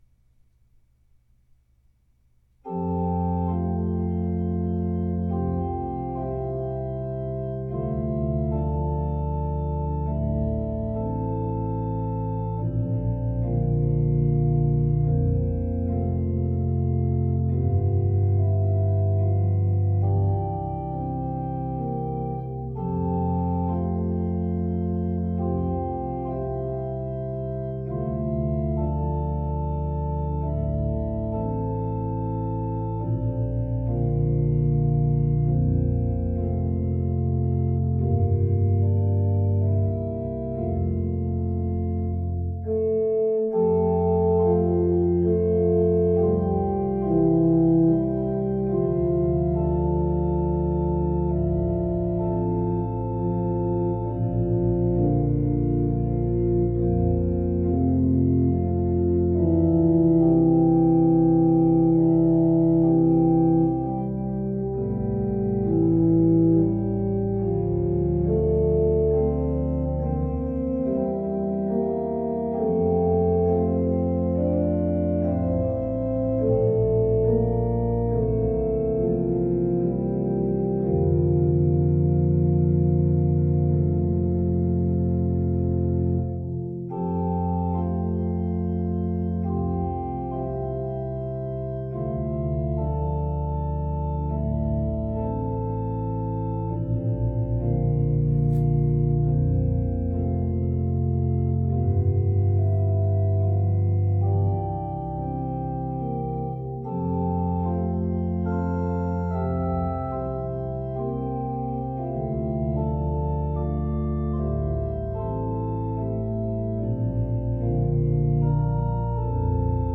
It’s intended to be a prelude.